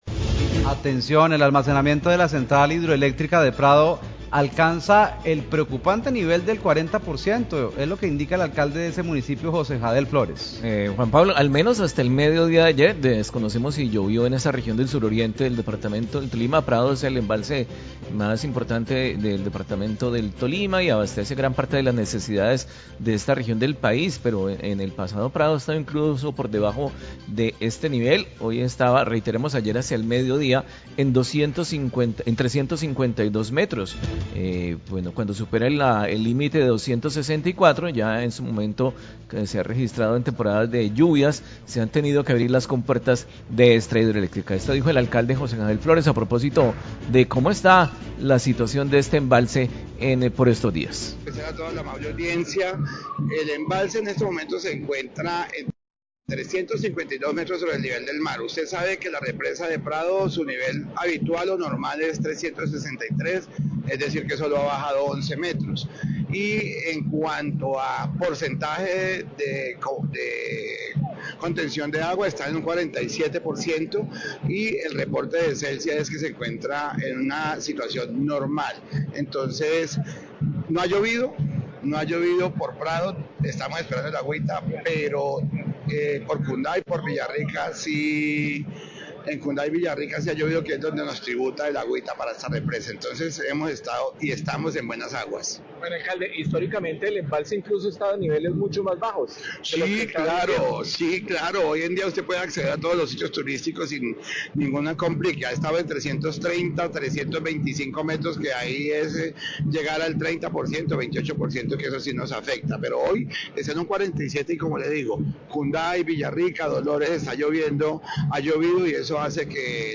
Alcalde de Prado habló acerca de la situación del embalse de ese municipio, Ecos del Combeima, 634am
Radio